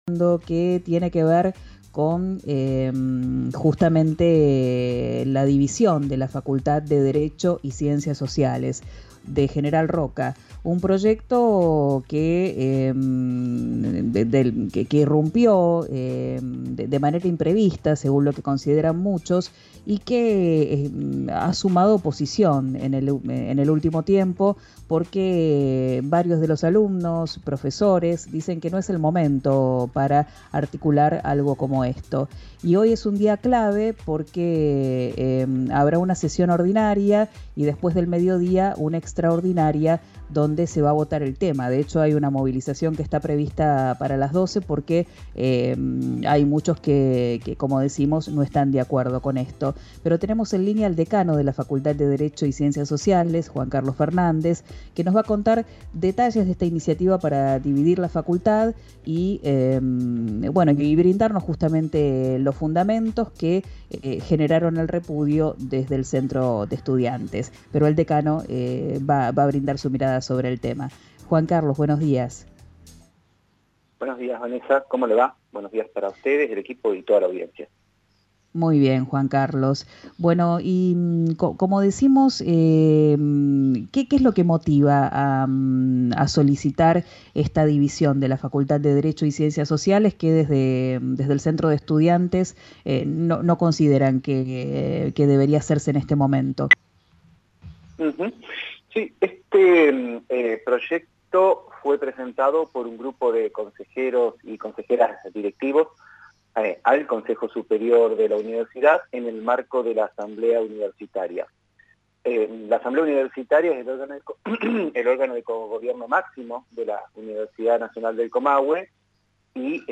compartió detalles sobre el proyecto de creación de nuevas facultades durante una entrevista en RÍO NEGRO RADIO.